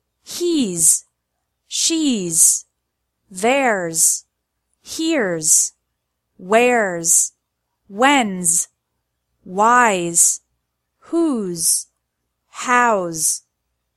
‘s is pronounced like Z in these words: